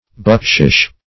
Bukshish \Buk"shish\, n.